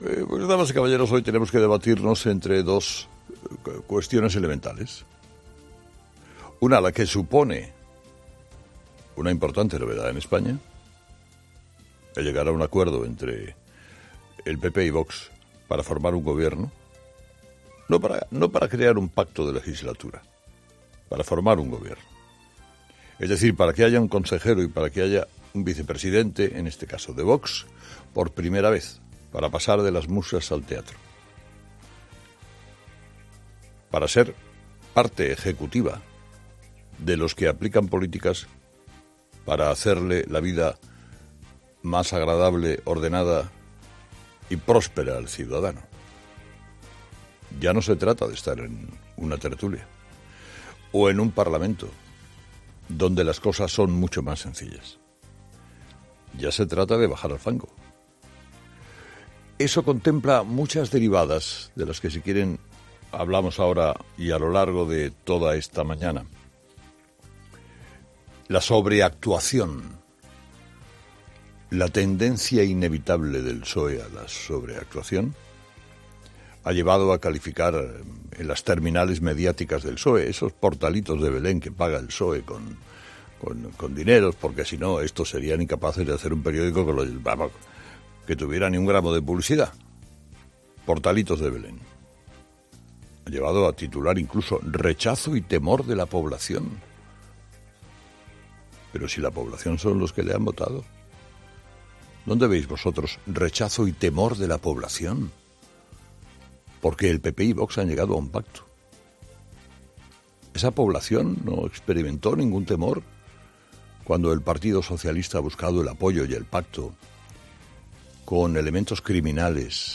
Carlos Herrera, director y presentador de 'Herrera en COPE', ha comenzado el programa de este viernes analizando las principales claves de la jornada, que pasan, entre otros asuntos, por el 18 aniversario de los atentados del 11-M en Madrid y por el acuerdo entre PP y Vox en Castilla y León para formar gobierno.